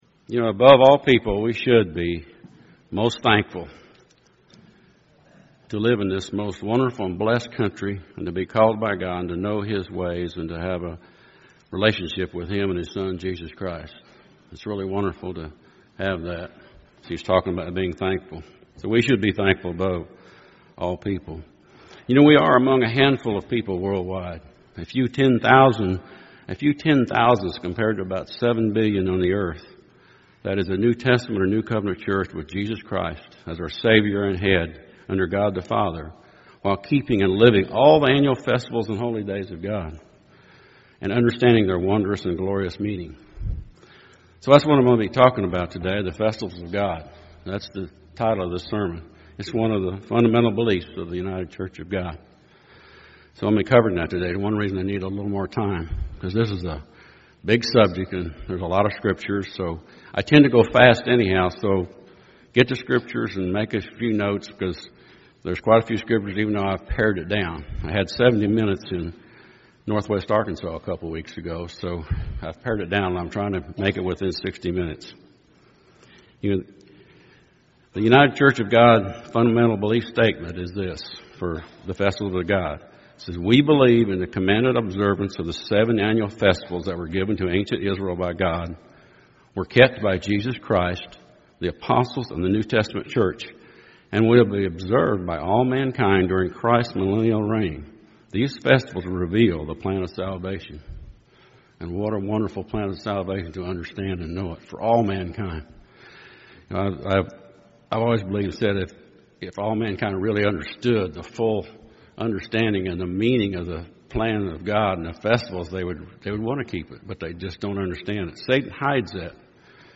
This sermon is a summary of the Festivals of God that are for both Old and New Covenant Christians.